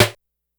Snares
Snare_20.wav